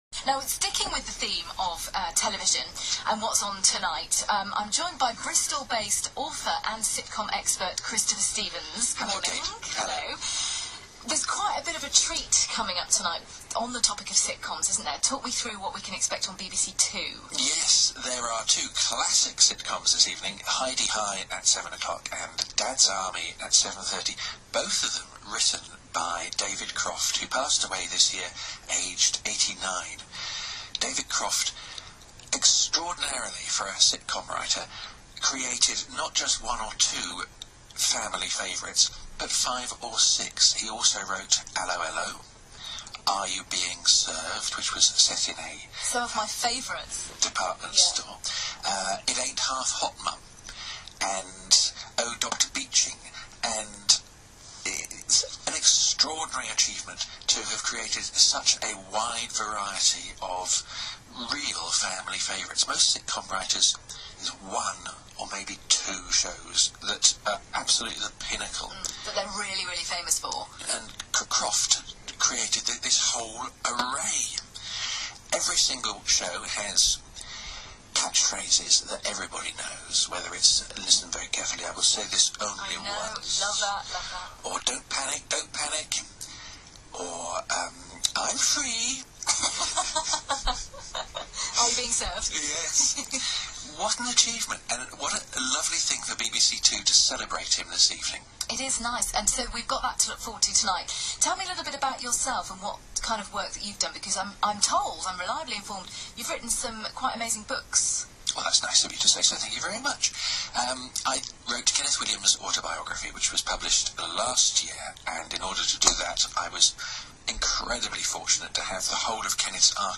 Includes a terrible impression of Ray Winstone...